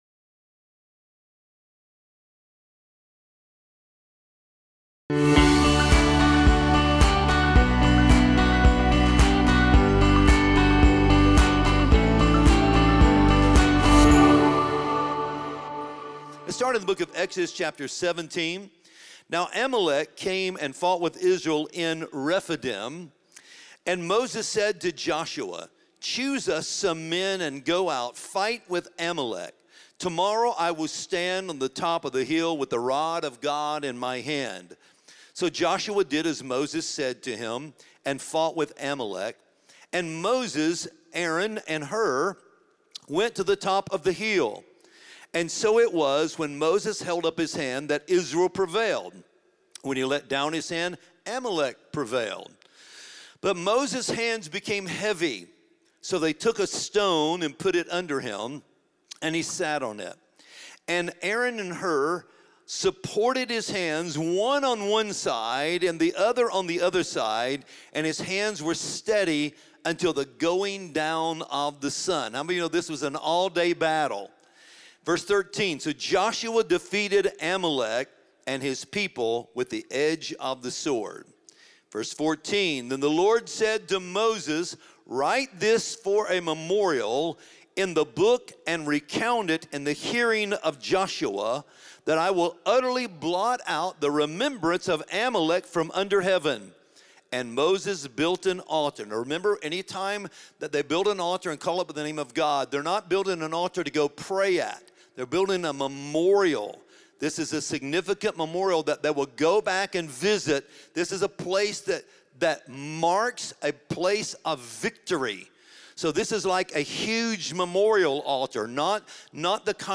This sermon explores God’s protection, peace, and promises when life feels heavy, offering biblical hope and encouragement.